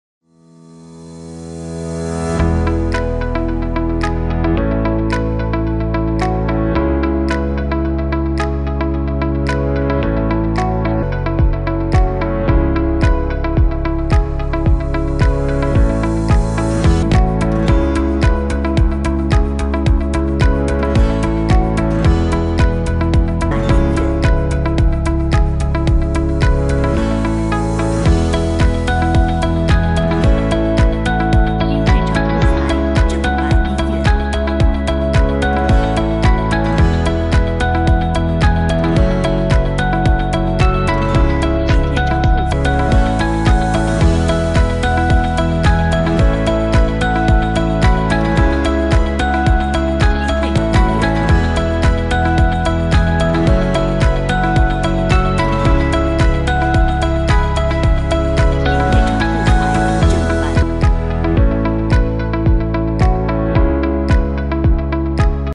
大气磅礴